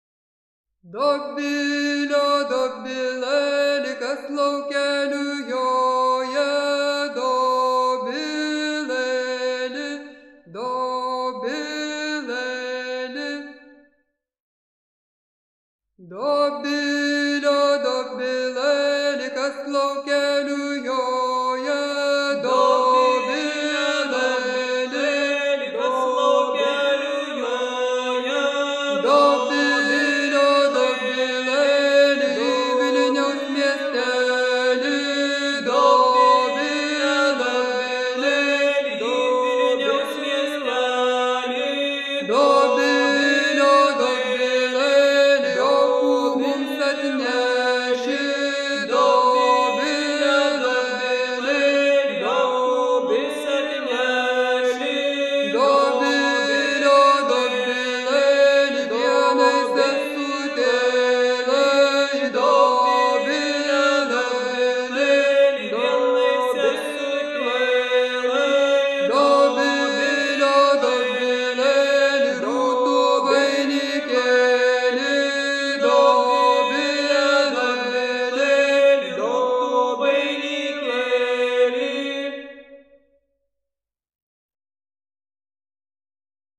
II.3 sutartine.mp3